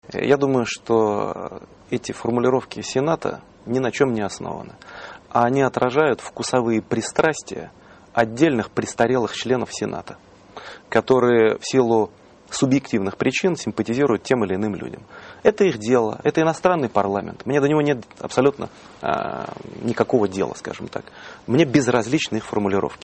Dmitry Medvedev on U.S. Senators in an interview broadcast by Russia's Ekho Moskvy radio, Russia Today television and Georgia's PIK-TV at his seaside residence in Russia's southern city of Sochi on 05aug2011.